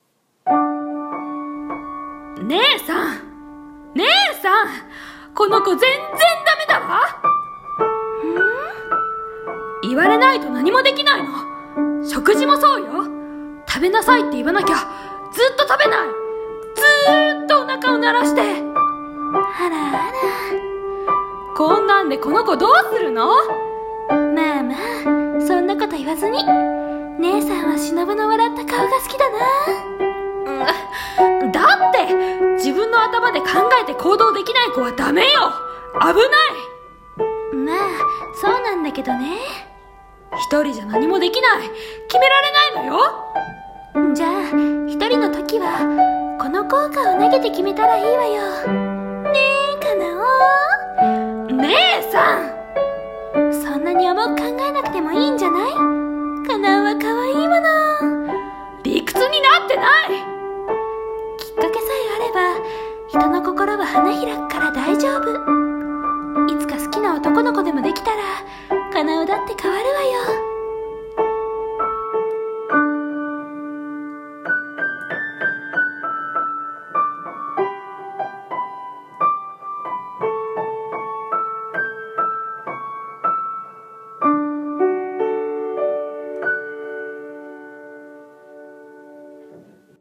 胡蝶姉妹 一人二役してみた